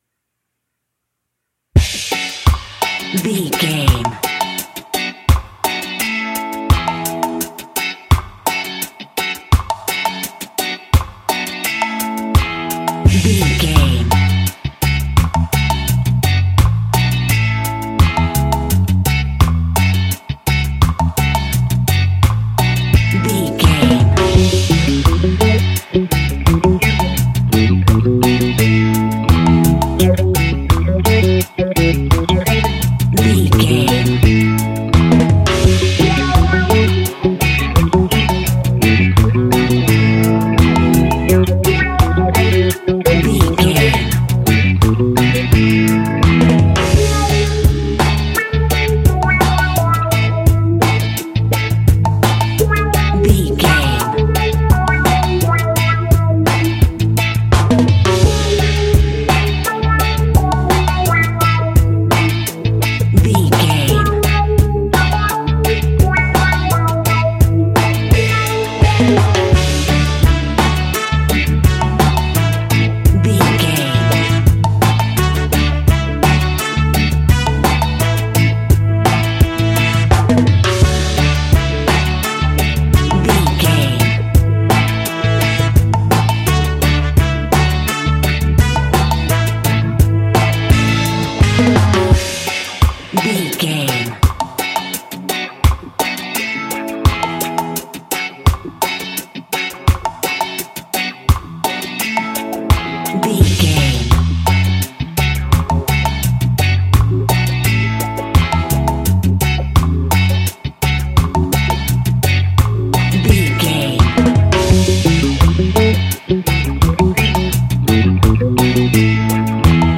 Classic reggae music with that skank bounce reggae feeling.
Uplifting
Aeolian/Minor
F#
reggae
laid back
chilled
off beat
drums
skank guitar
hammond organ
percussion
horns